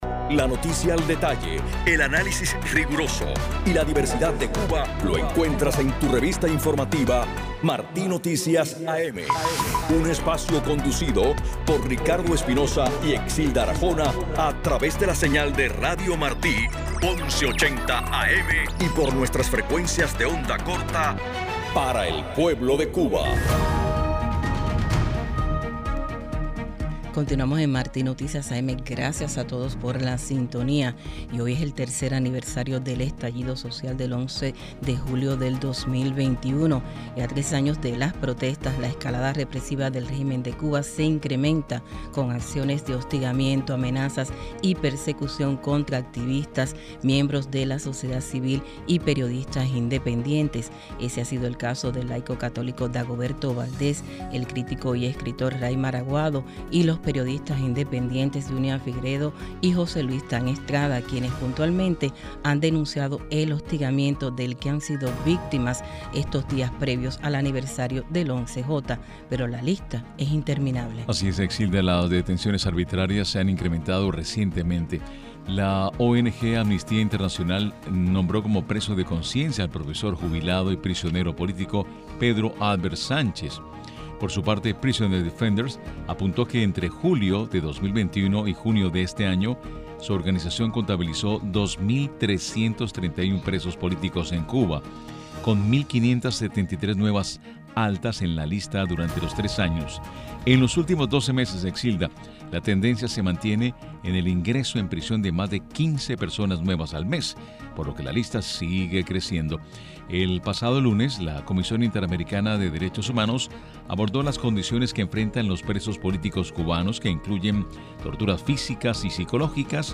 Durante la entrevista, el experto insistió en que “uno de los puntos sensibles es la situación de los prisioneros políticos a raíz de las protestas populares, donde a pesar de la petición de amnistía por varios organismos internacionales, el régimen no da atisbos de implementarla”.